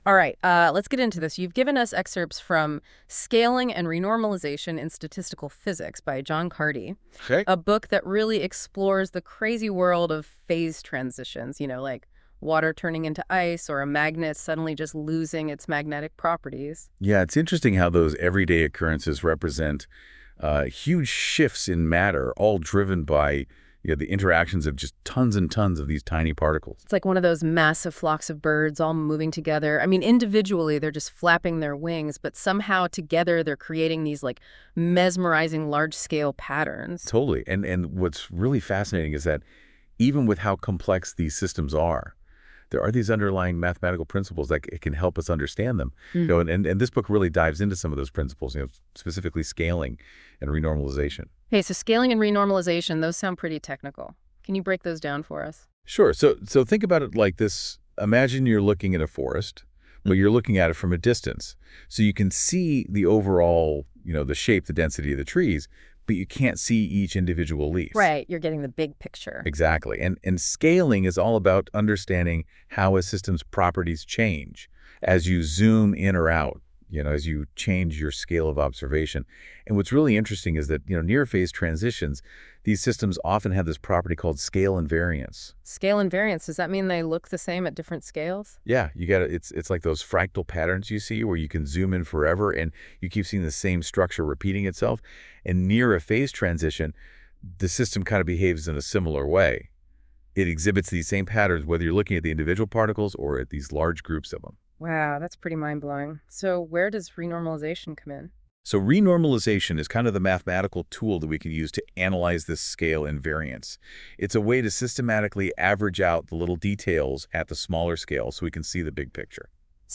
using NotebookLM powered by Google Gemini.